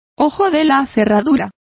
Complete with pronunciation of the translation of keyhole.